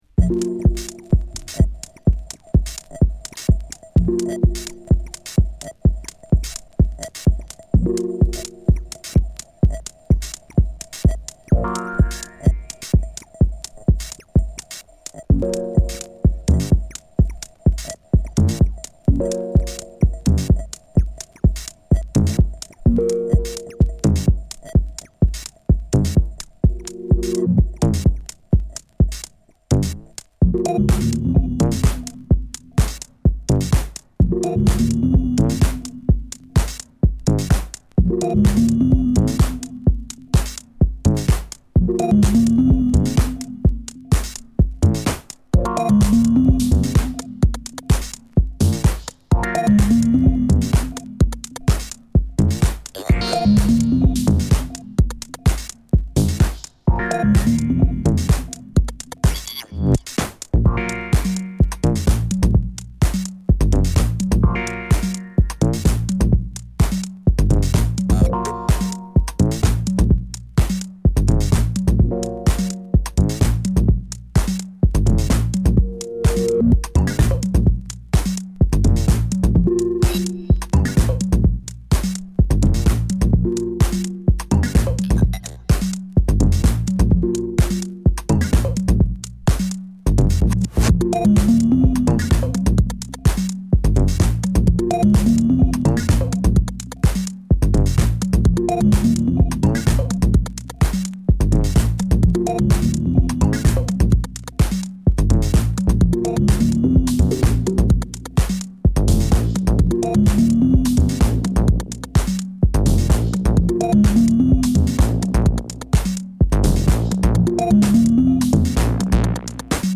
Minimal , Sold Out , Tech House , Techno